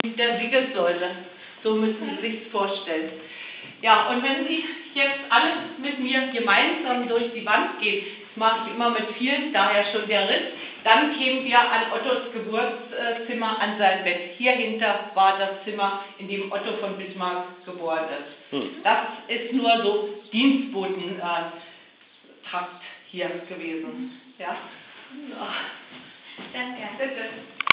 Bei dem Bismarck-Museum in Schönhausen / At the Bismarck Museum in Schoenhausen
Die Führerin sagt uns, wo Otto von Bismarck geboren ist. / The guide tells us where Otto von Bismarck was born.